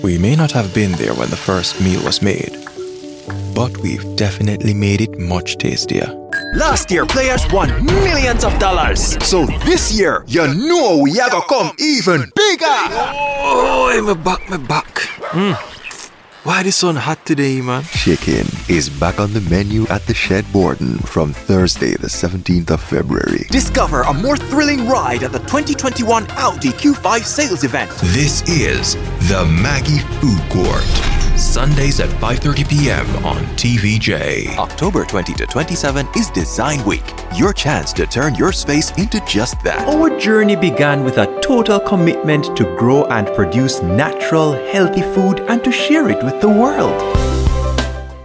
English (Jamaican)
English (Caribbean)
Friendly
Experienced
Confident